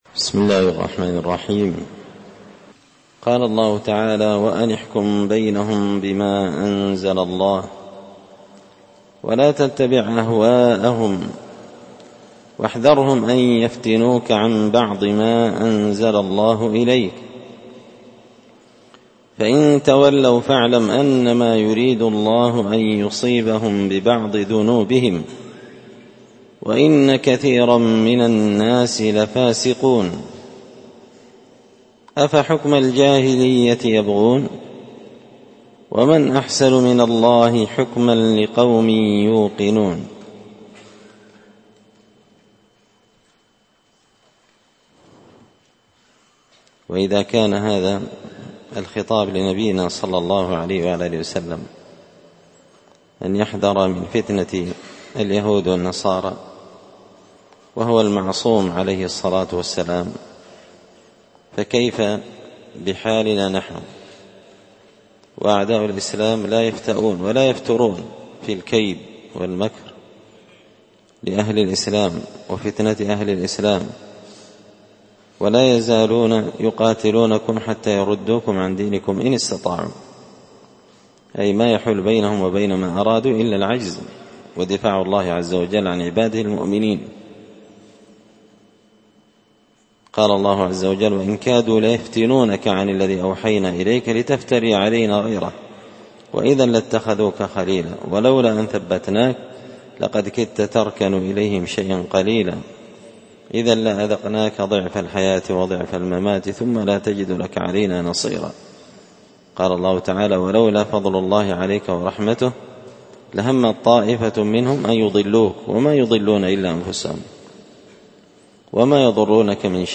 📢 ألقيت هذه الدروس في 📓 # دار _الحديث_ السلفية _بقشن_ بالمهرة_ اليمن 🔴مسجد الفرقان